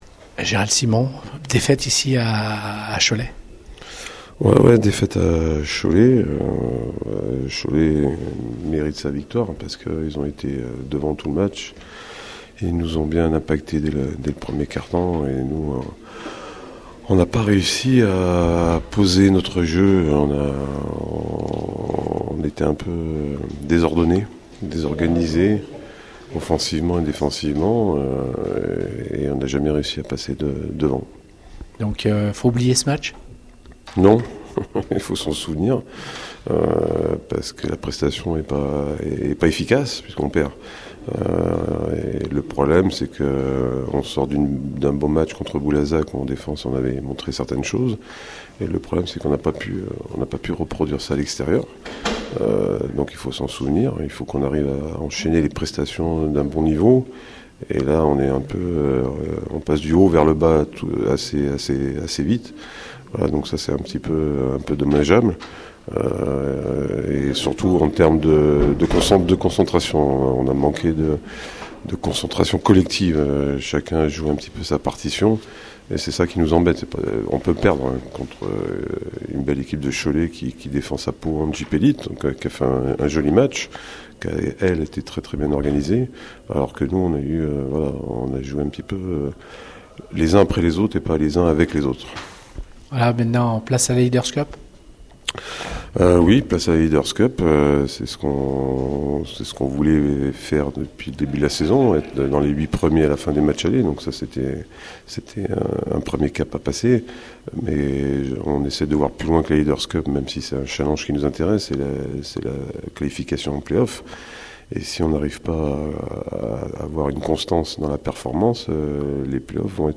Les interviews